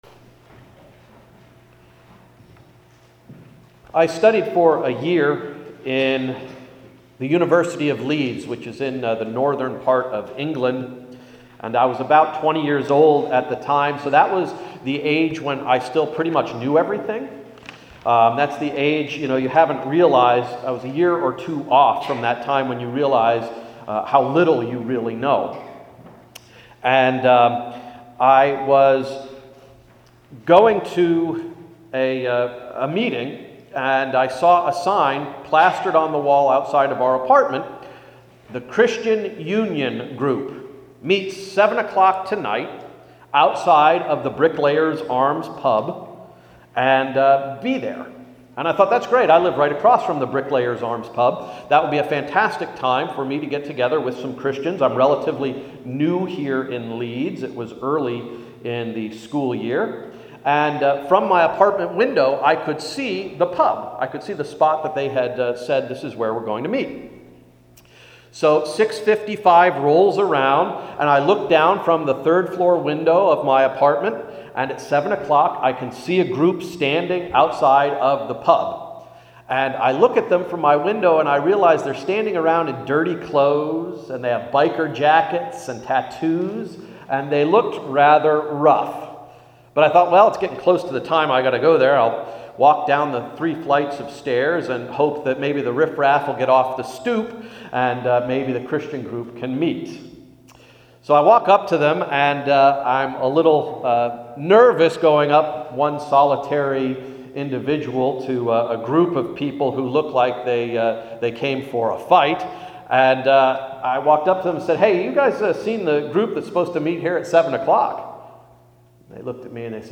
“Spiritual Sight”–Sermon of April 3, 2011
To hear Sunday’s Sermon, click here: Spiritual Sight